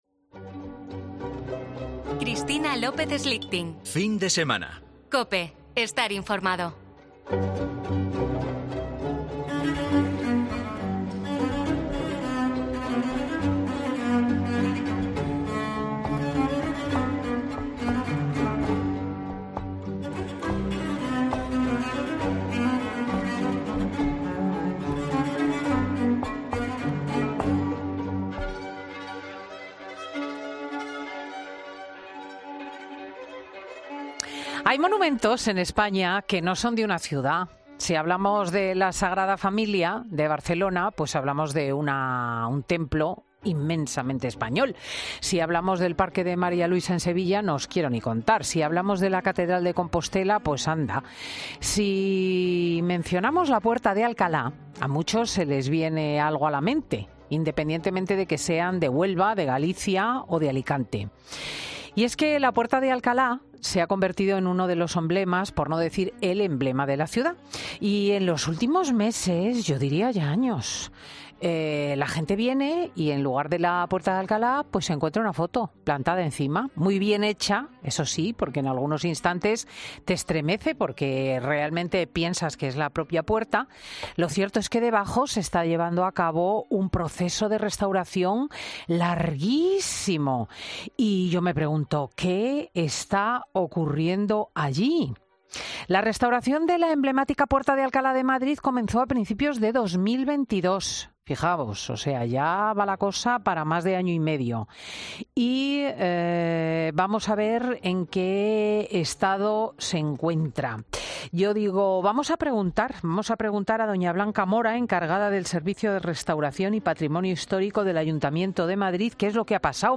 Redacción digital Madrid - Publicado el 09 jul 2023, 13:26 - Actualizado 09 jul 2023, 13:45 3 min lectura Descargar Facebook Twitter Whatsapp Telegram Enviar por email Copiar enlace Escucha ahora 'Fin de Semana' . "Fin de Semana" es un programa presentado por Cristina López Schlichting , prestigiosa comunicadora de radio y articulista en prensa, es un magazine que se emite en COPE , los sábados y domingos, de 10.00 a 14.00 horas.